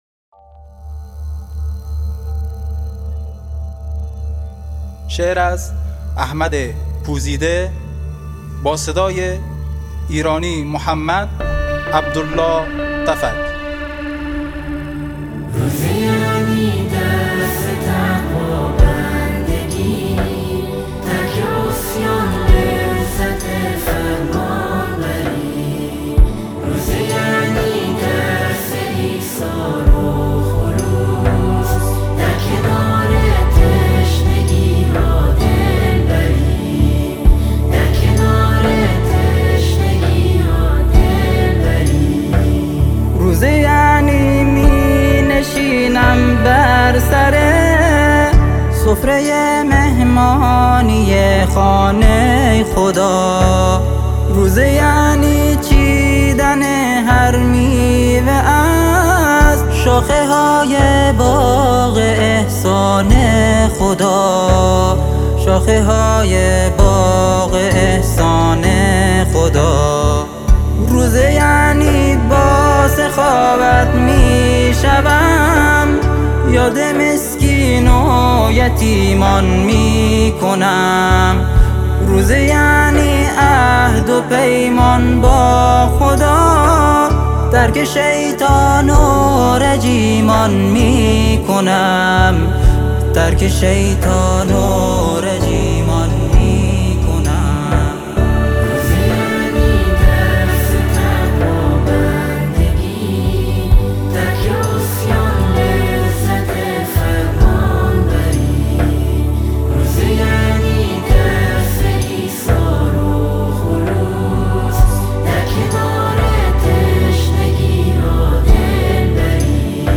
نشید زیبای "سی شکوفه"